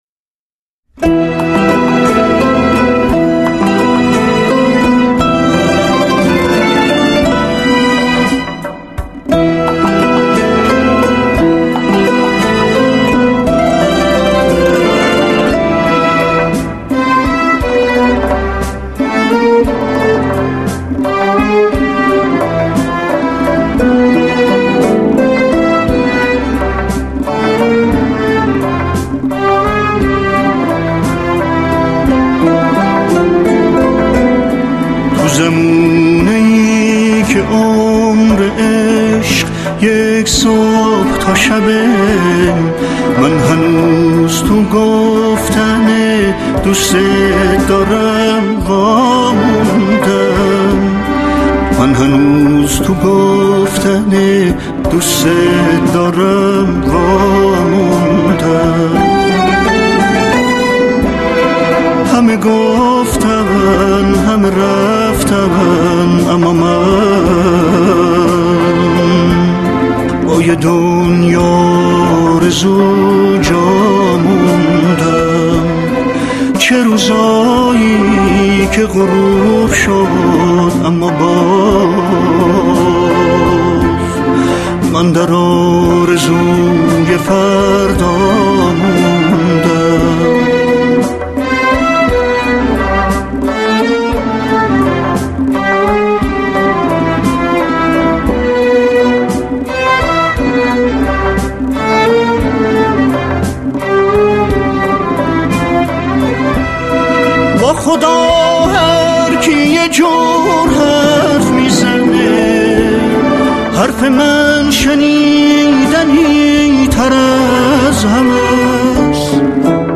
سنتی